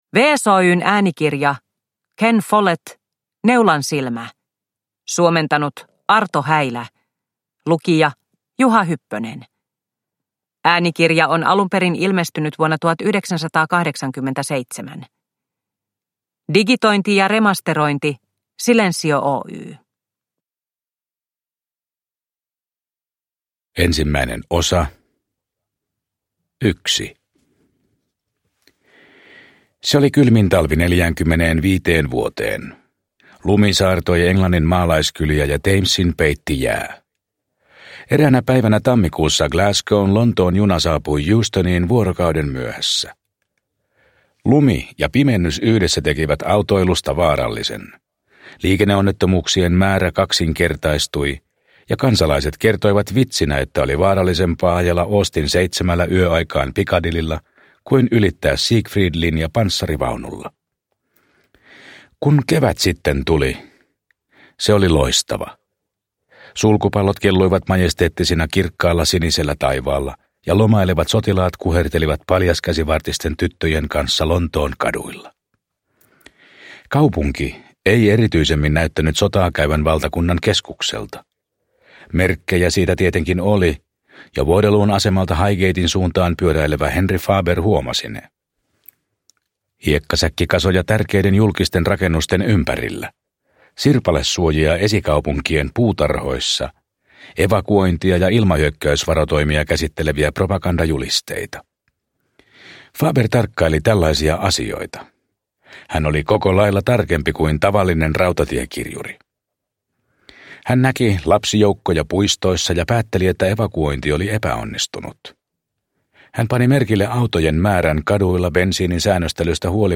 Neulansilmä – Ljudbok – Laddas ner
Äänikirja on ilmestynyt alun perin vuonna 1987.